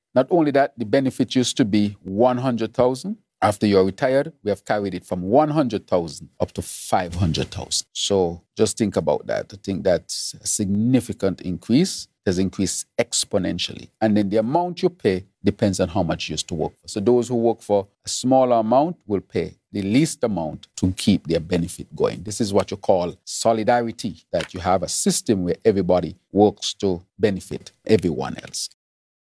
PM Drew continued to explain the health insurance: